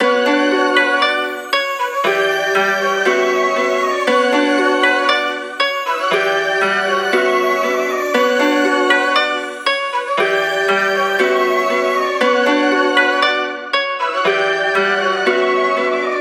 MELODY LOOPS
Sacrifices (118 BPM – Bm)